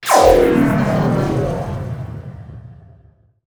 SPACE_WARP_Complex_05_stereo.wav